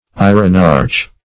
Eirenarch \Ei`re*narch\, n. [See Irenarch.]